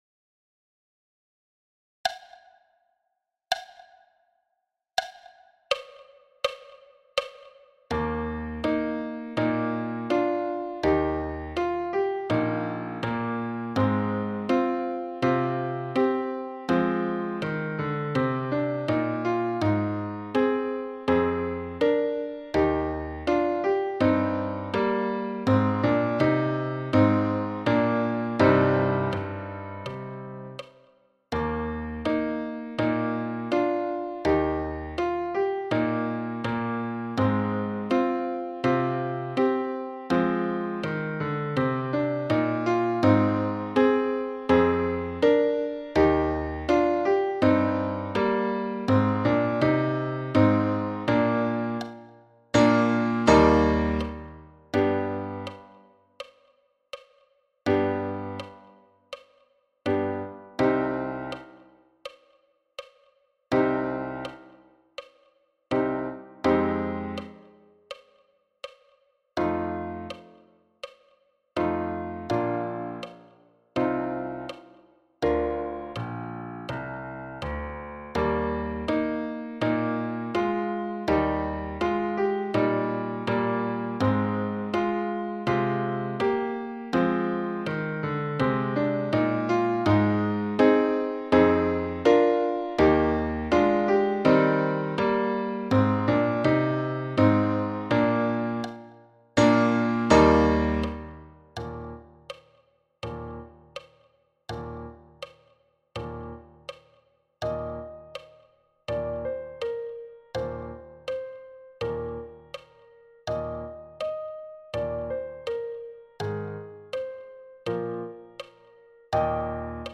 Pathfinder – piano à 72 bpm
Pathfinder-piano-a-72-bpm.mp3